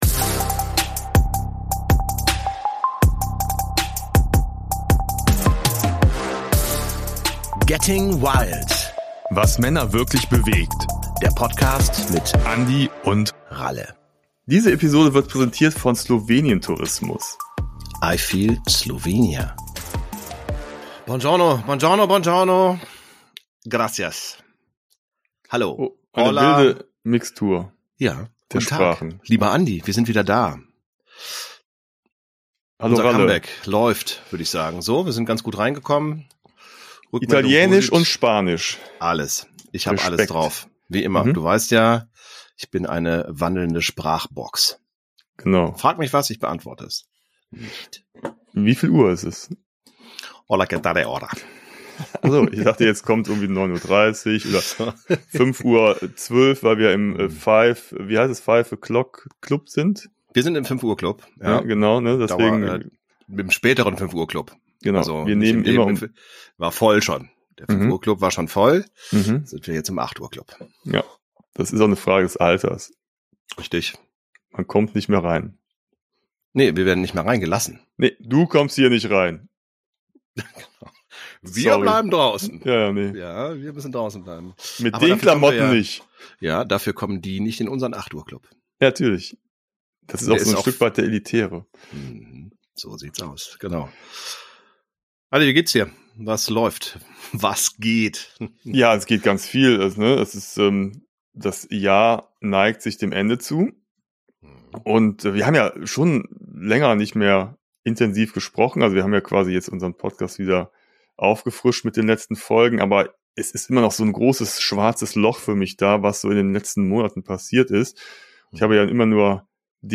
Budapest, Olympia und EM: Rückblick auf einen wilden Sommer ~ Getting Wyld - Was Männer wirklich bewegt. Der Talk